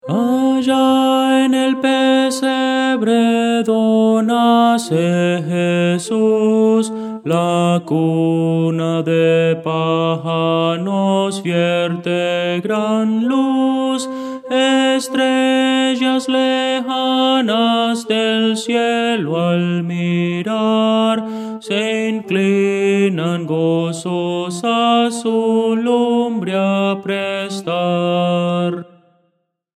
Voces para coro
Tenor – Descargar